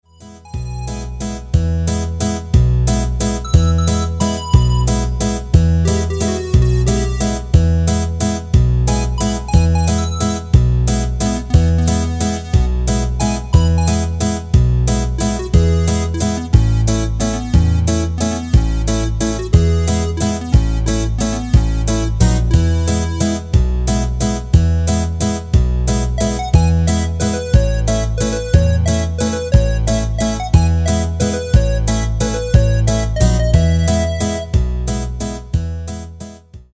Rubrika: Folk, Country
Karaoke